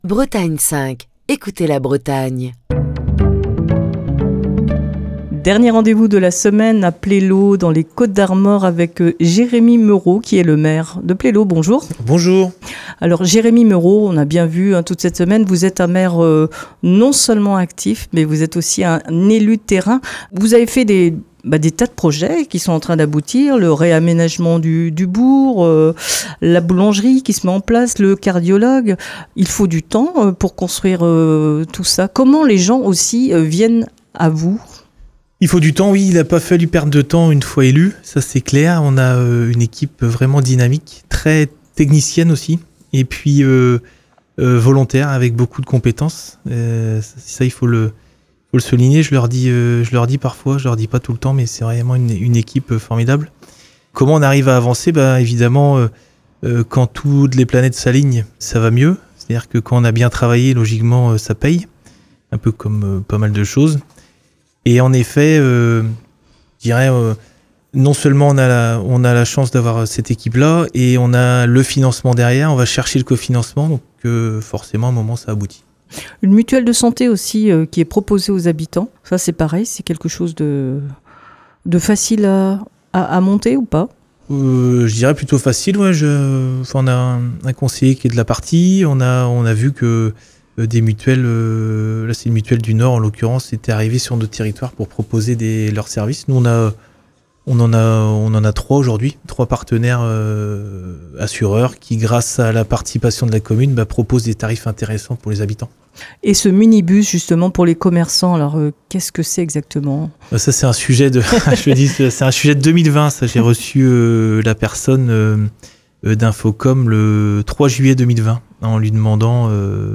Ce vendredi, dernier rendez-vous à Plélo, dans les Côtes d'Armor pour Destination commune. Jérémy Meuro, le maire de Plélo, vous invite à découvrir sa commune au micro